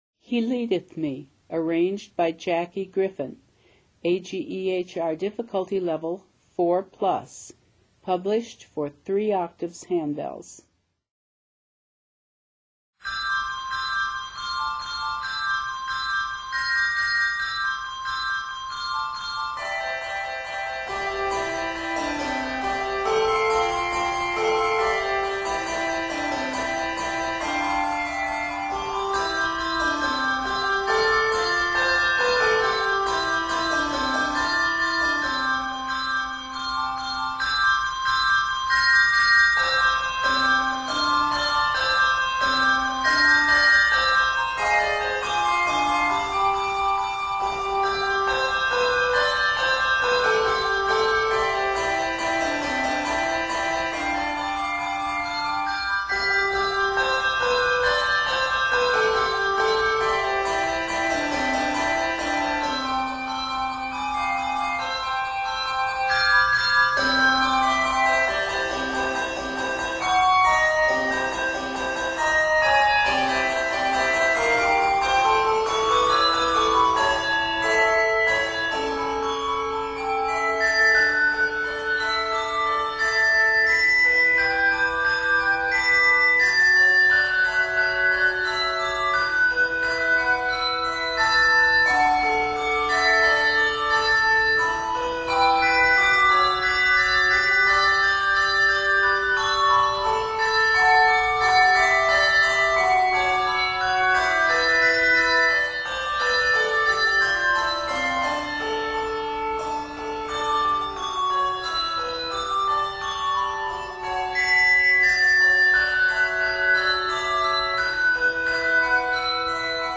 set in they keys of C Major and D Major